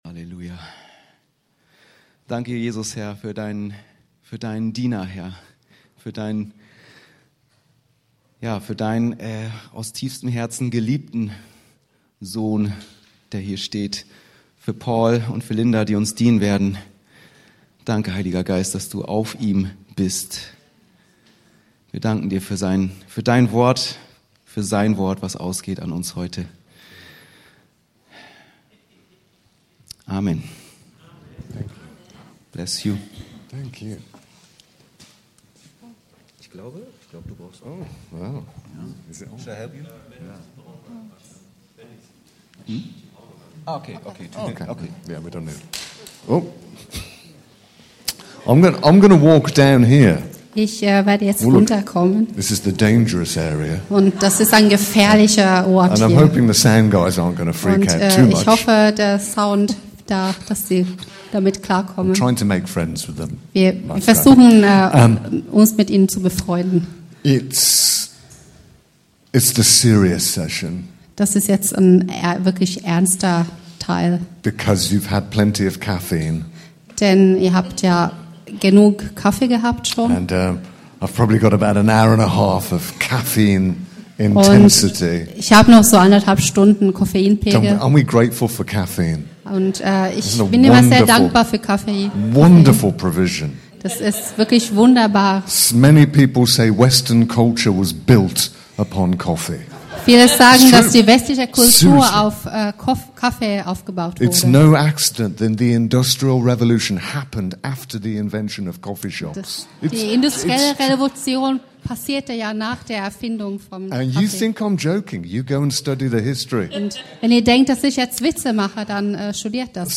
ENTFACHT! - AKD-Konferenz 2026 - Samstag Vormittag